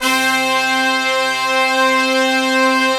C4 POP BRASS.wav